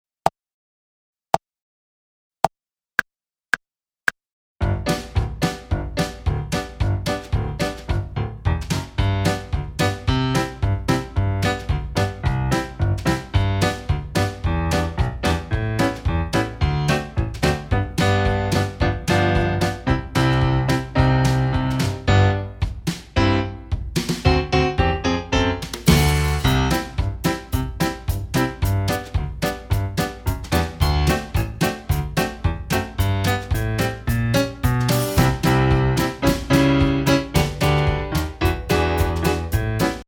Upbeat songs that teach, not preach!